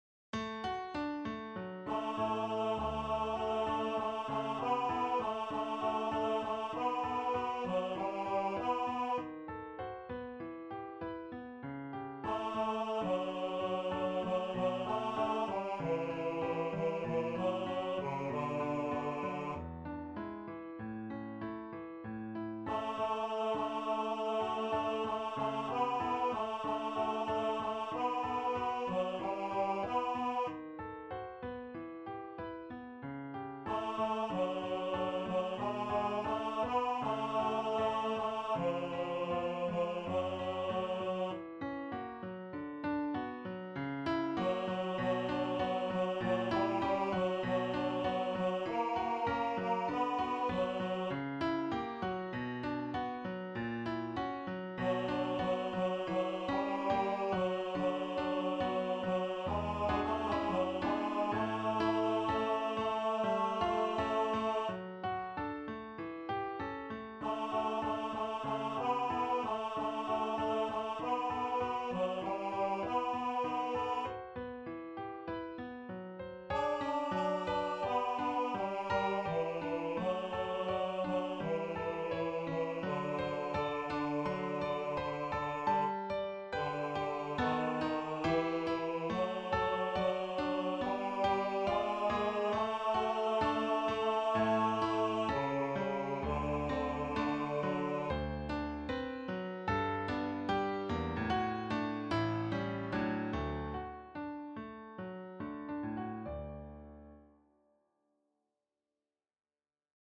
SINGEN: Lieder und Arien für Bass/Bariton